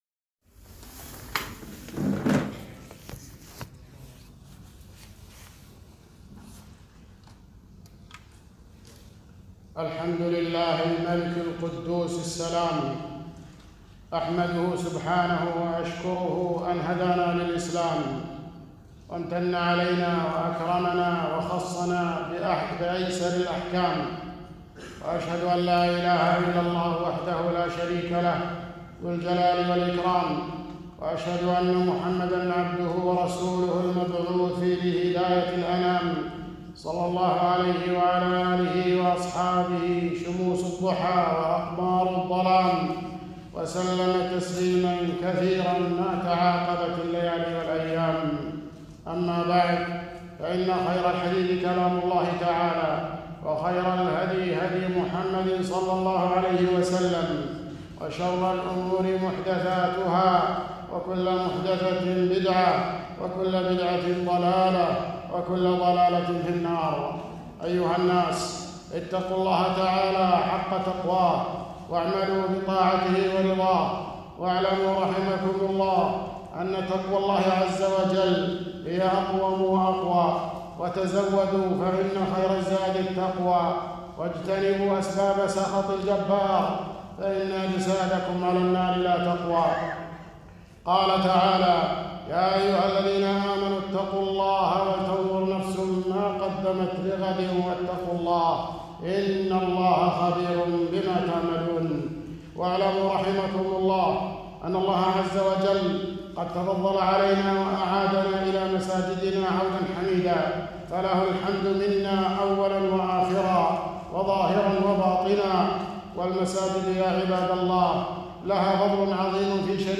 خطبة - فضل المساجد وفضل عشر ذي الحجة 3 ذو الحجة 1441 هــ